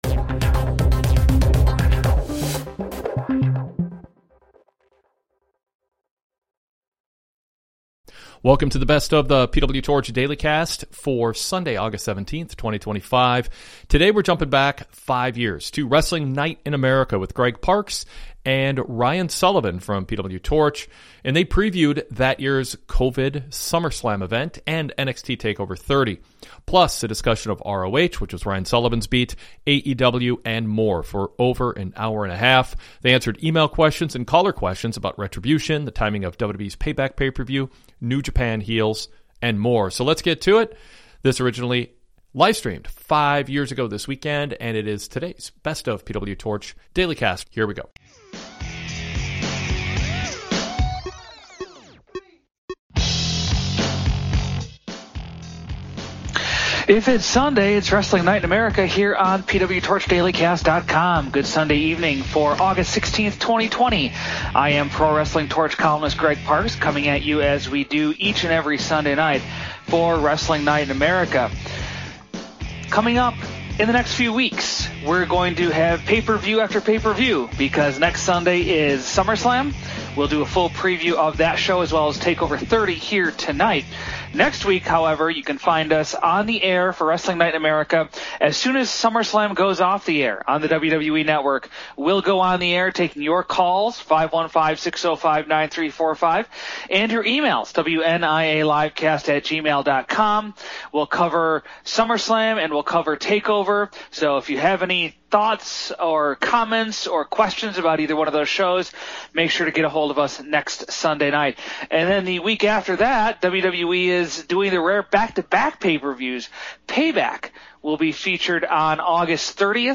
They also answer email and caller questions about Retribution, the timing of WWE’s Payback PPV, New Japan heels, and more.